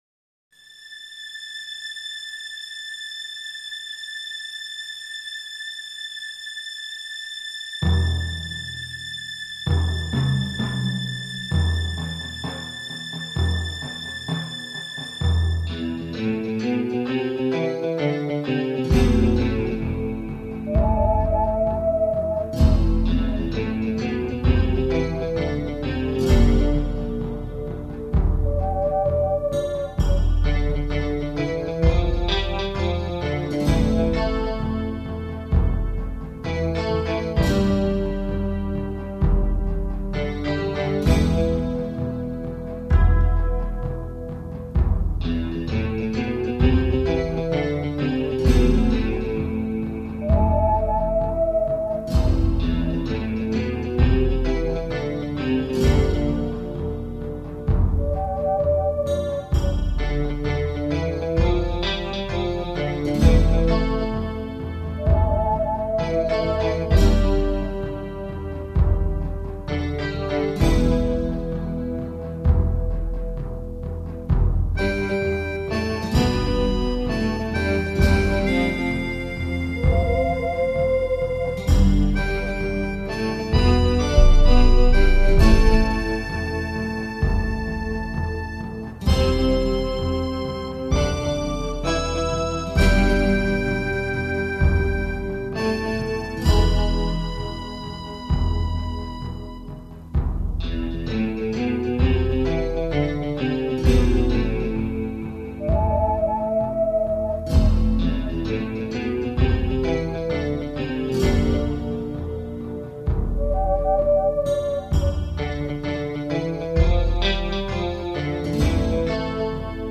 年末なのでサンプル載せますが容量を少なくするので音質は落としてます。
♪劇中曲サンプル